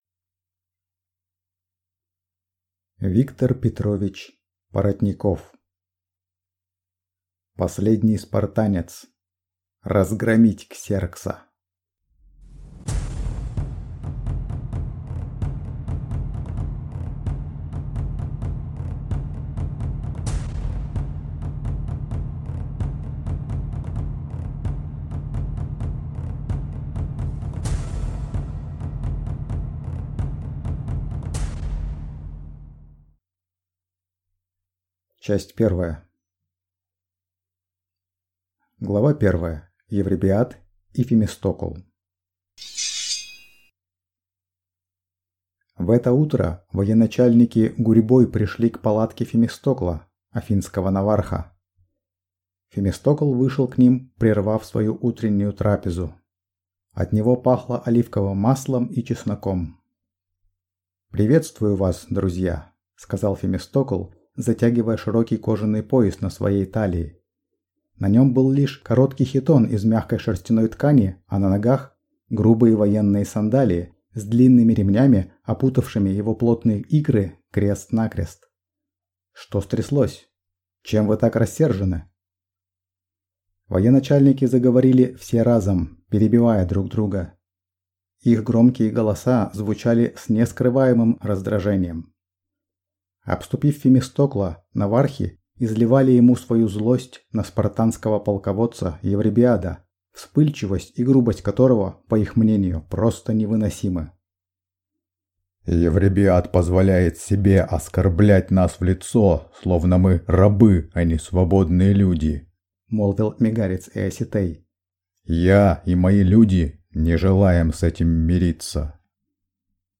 Аудиокнига Последний спартанец. Разгромить Ксеркса!